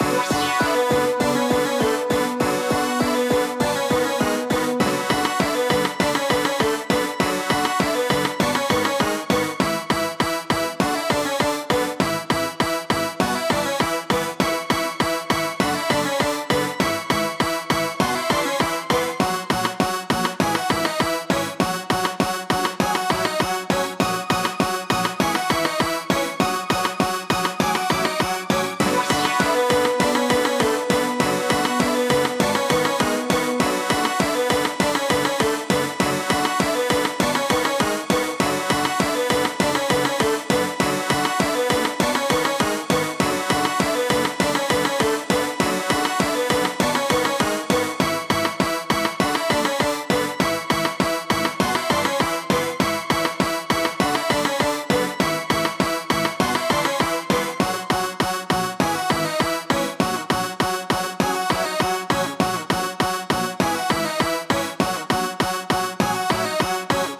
ちょっとレトロなサイバー系バトルBGMです！
ループ：◎
BPM：200 キー：Bm ジャンル：おしゃれ、みらい 楽器：シンセサイザー